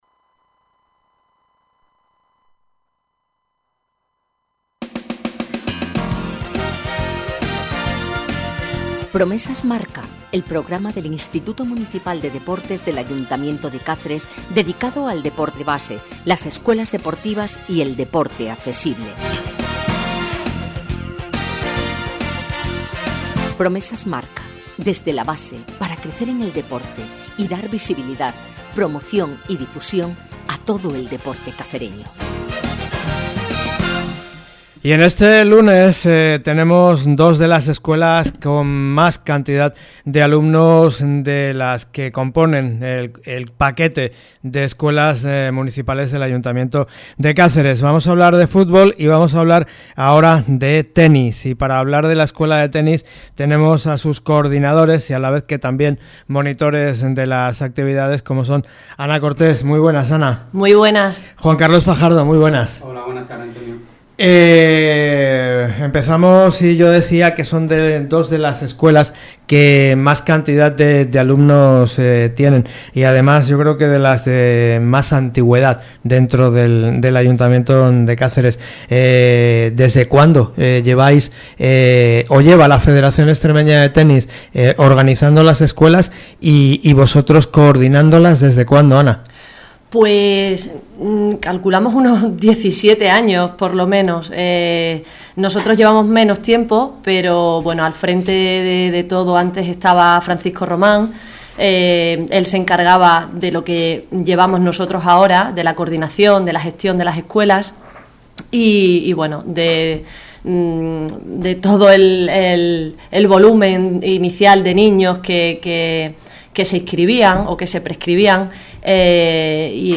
Esta es la entrevista